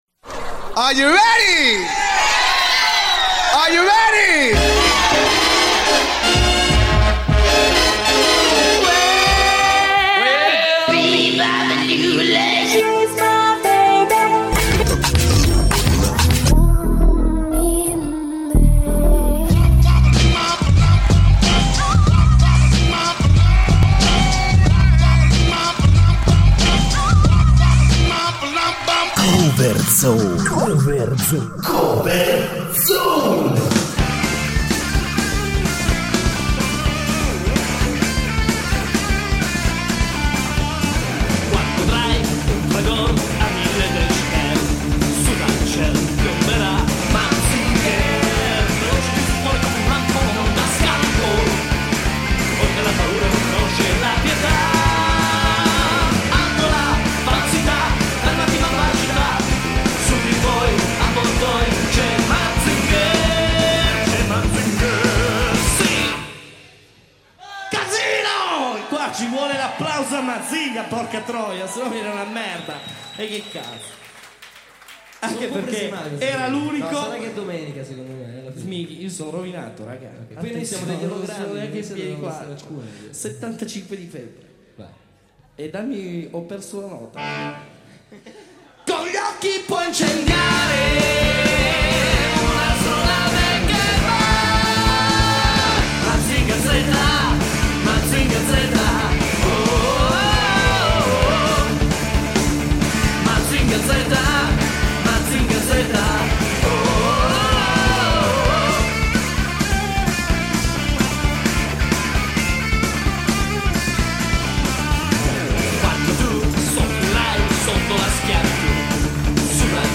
funkoWorldMusic